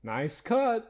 Add voiced sfx
nicecut3.ogg